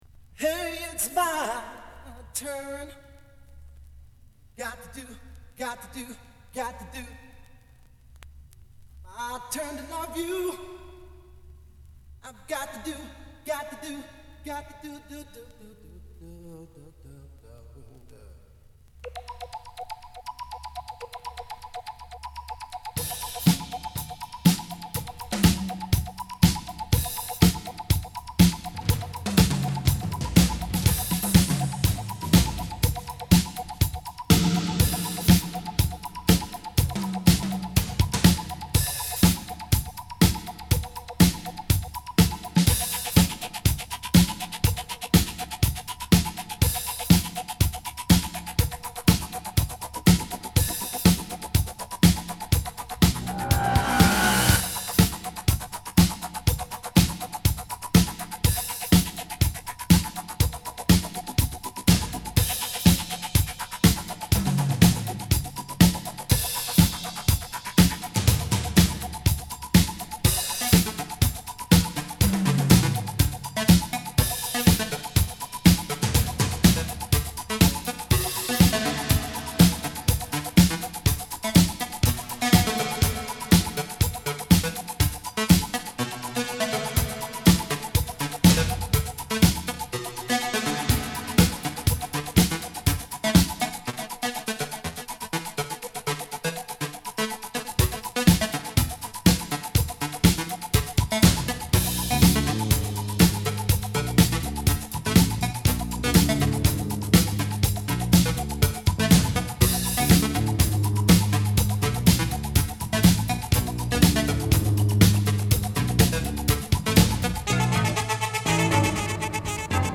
Dub Version
at Sigma Sound, New York City
lead vocals
synthesizers
bass
drums.